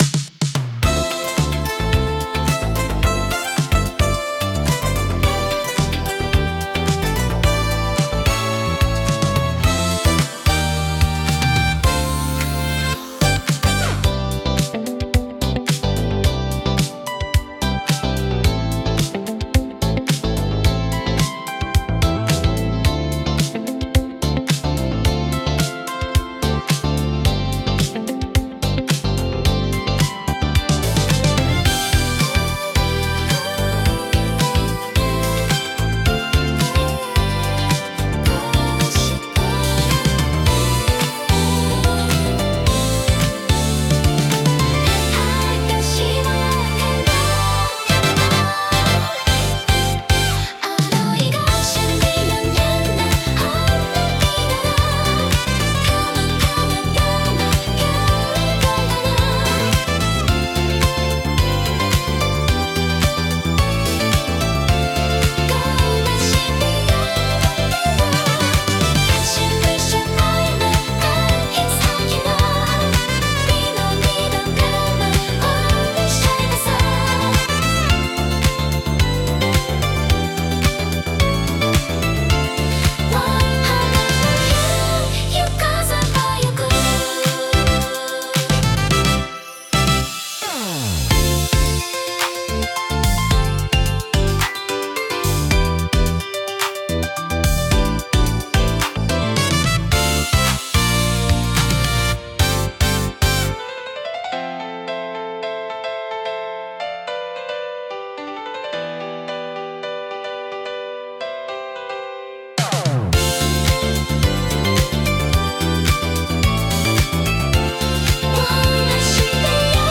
シティポップは、1970～80年代の日本で生まれたポップスの一ジャンルで、都会的で洗練されたサウンドが特徴です。